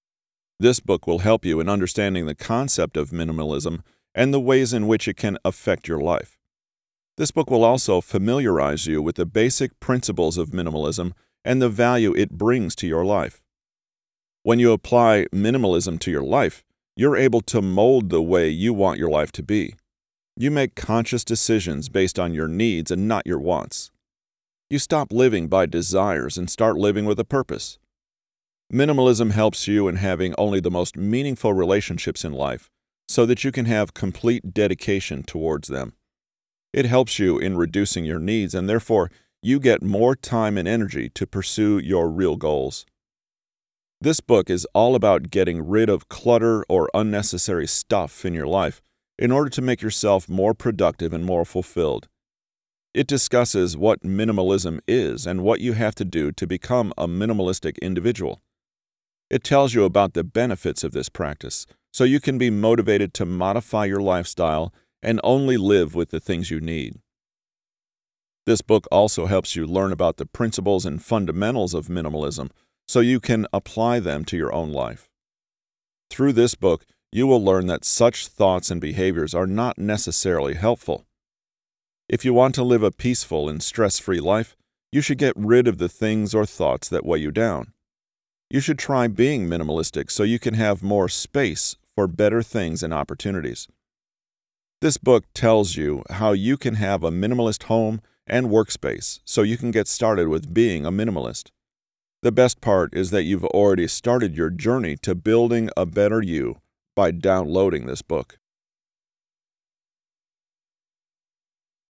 Categories: Audiobook, Production News, Voice over WorkTags: , , ,